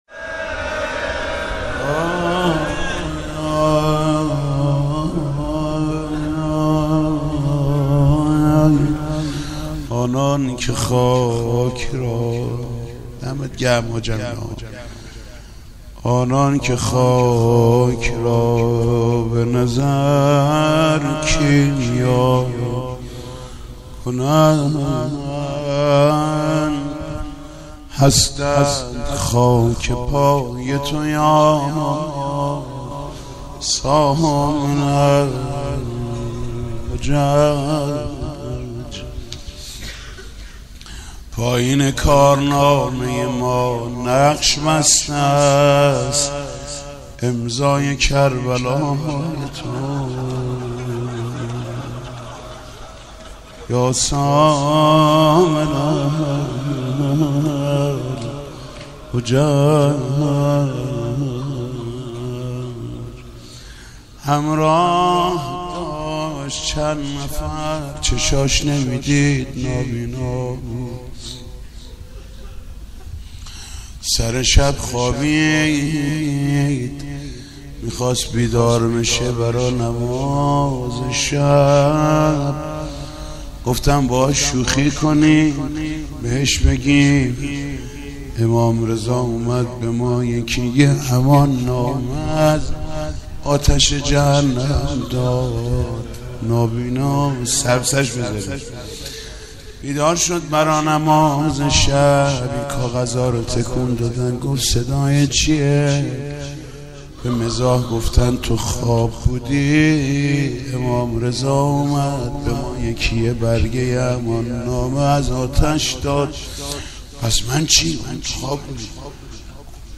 مناسبت : شهادت امام رضا علیه‌السلام
مداح : محمود کریمی قالب : روضه